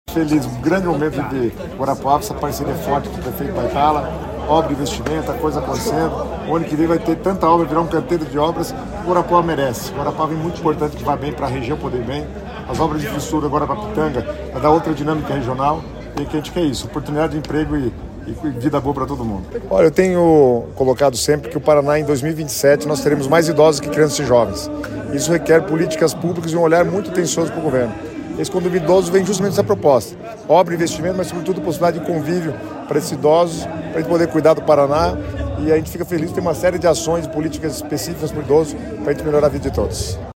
Sonora do secretário das Cidades, Guto Silva, sobre o Condomínio do Idoso de Guarapuava